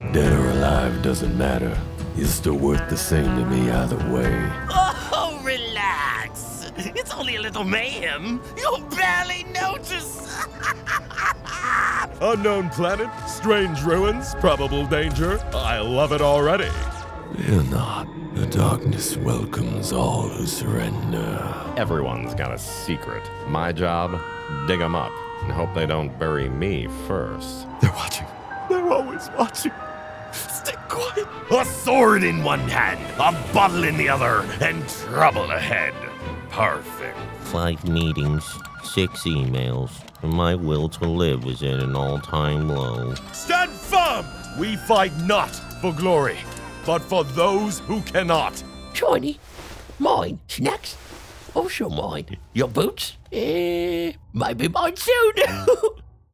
Male
Deep, authentic, engaging and warm male voice, able to bring both the hype and energy of a high-octane commercial promo and the intimacy of an emotionally charged, empathetic character read.
Character / Cartoon
Character Reads For A Grizzled Outlaw, Evil Clown Villain, Space Hero, Cult Leader, Detective, Paranoid Victim, Pirate Captain, Dejected Office Worker, Heroic Medieval Warrior, And A Silly Little Goblin
Words that describe my voice are Deep, Engaging, Authentic.